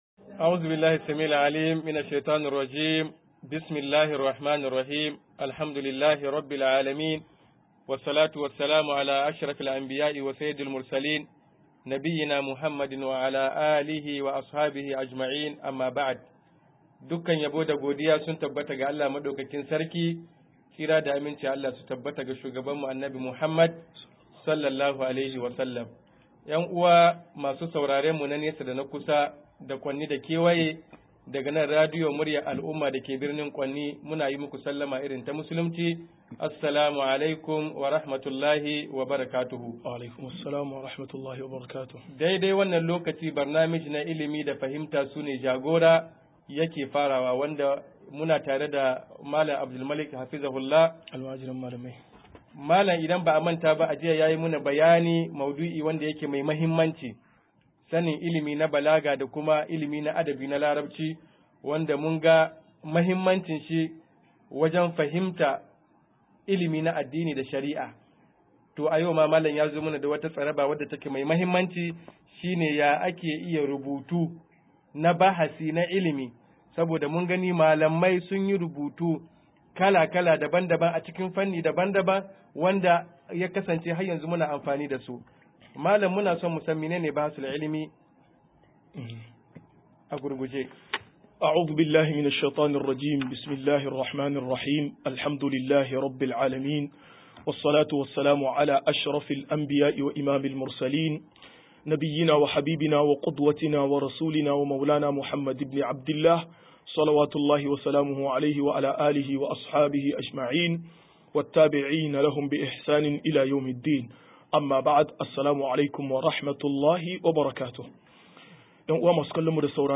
176-Ka idodin Bincike - MUHADARA